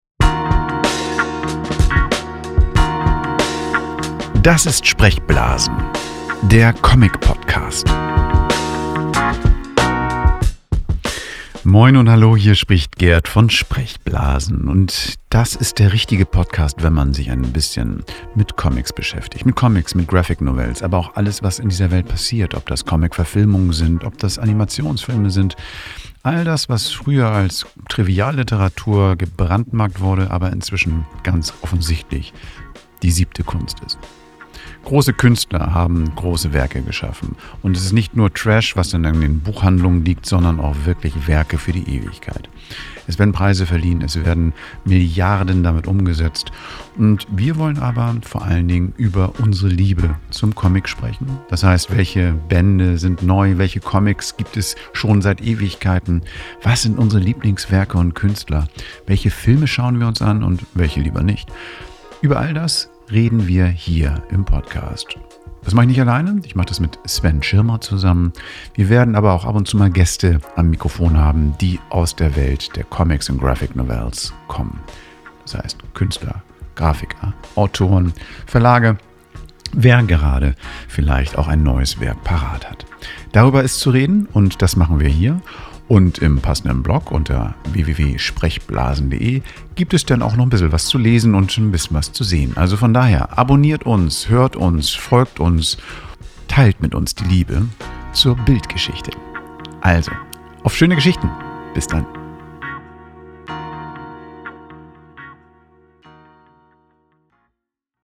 Das Team von "Sprechblasen – Der Comic-Podcast" stellt sich in diesem Trailer vor.